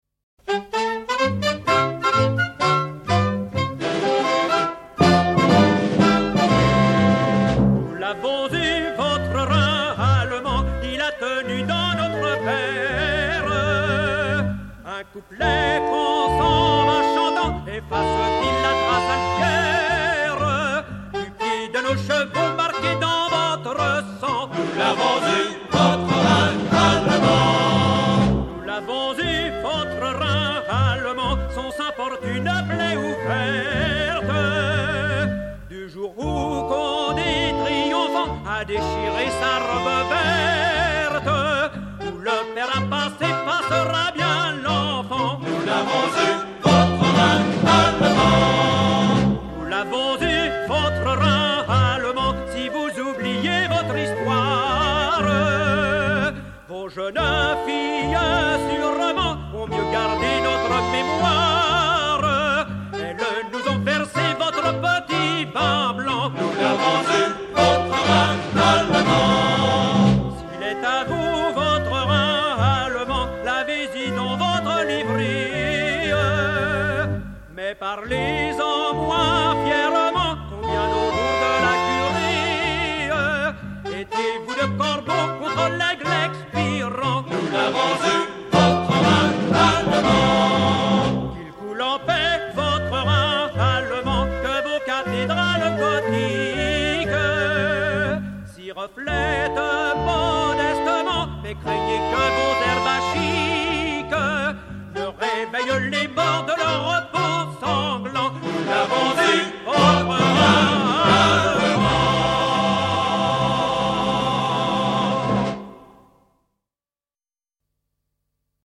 ténor français